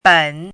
běn
ben3.mp3